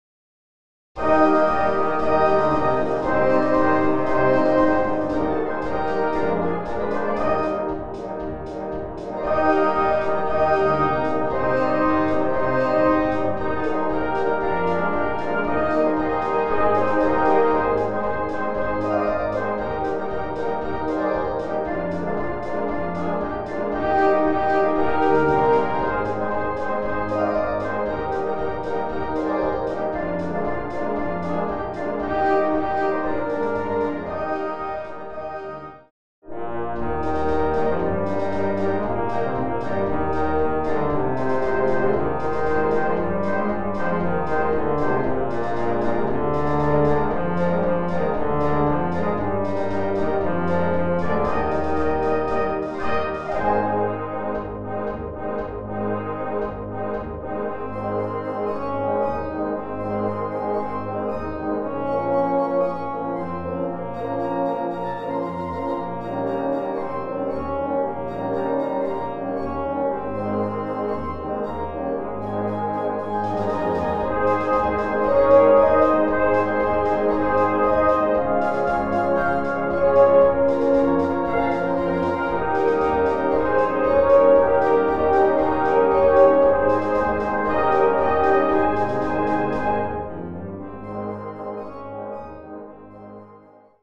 Im Gegensatz, das Trio, mit einer weichen Melodieführung.